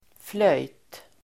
Ladda ner uttalet
flöjt substantiv, flute Uttal: [flöj:t] Böjningar: flöjten, flöjter Definition: ett musikinstrument Sammansättningar: tvärflöjt (flute), blockflöjt (recorder) flute substantiv, flöjt , flöjtinstrument